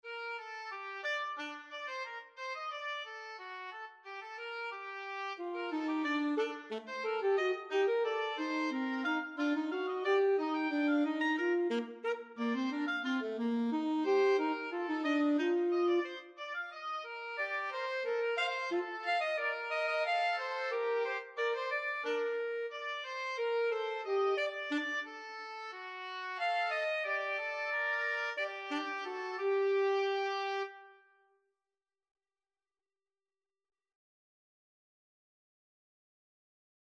The theme of this piece involves 18 notes and also lasts for two bars. It can be heard the first time being played by the oboe, starting on the minor third (Bb).
The voice for the saxophone starts in bar 3 with a variation of the theme, starting on f and is followed by an inversion on d. From the end of bar 7 to the beginning of bar 9 another variation can be heard, also starting on d. The last for bars only involve short motives from the theme.